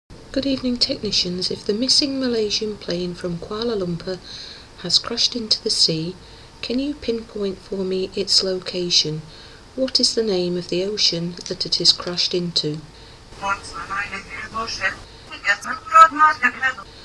Voice EVP
Isolated clip – won’t survive Indian Ocean – slowed down slightly